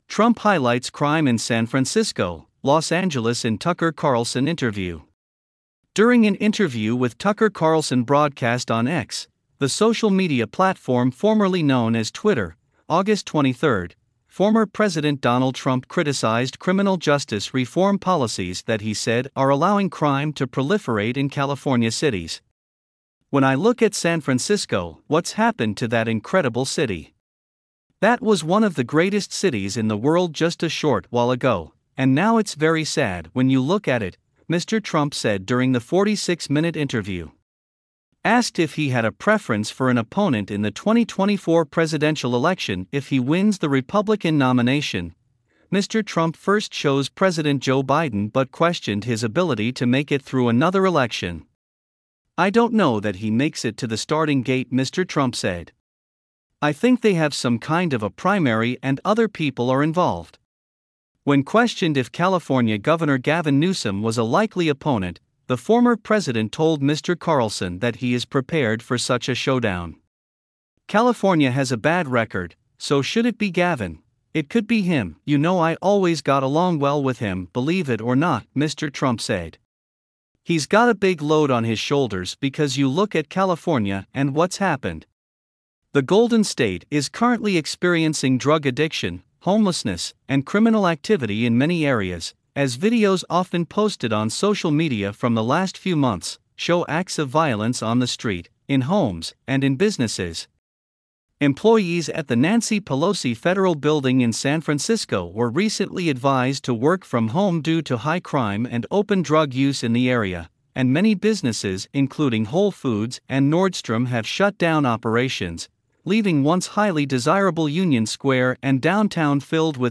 Trump Highlights Crime in San Francisco, Los Angeles in Tucker Carlson Interview | California Insider